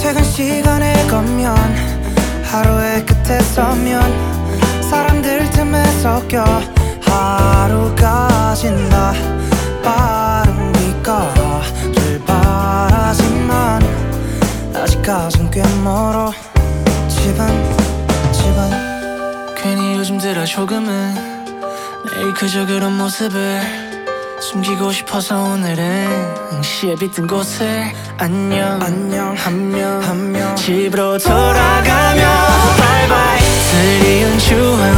Жанр: Поп музыка / Рок
K-Pop, Pop, Rock